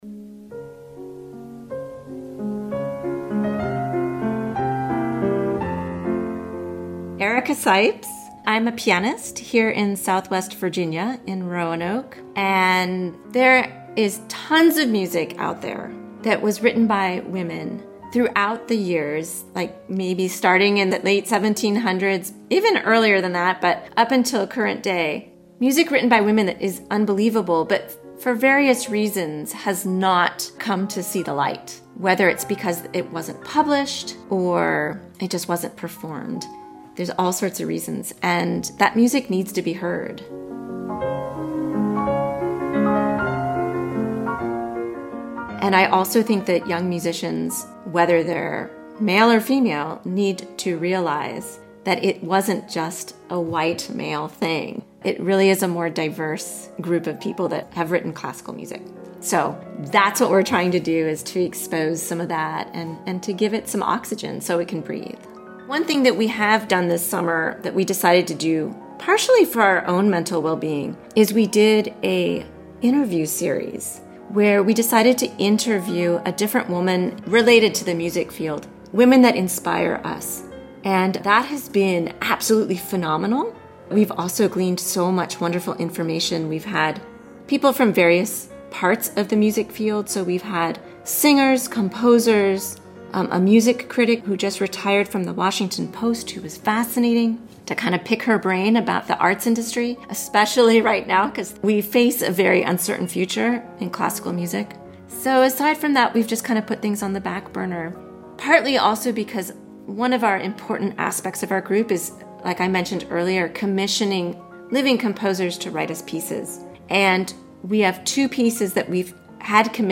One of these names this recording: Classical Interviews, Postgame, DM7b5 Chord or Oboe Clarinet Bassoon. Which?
Classical Interviews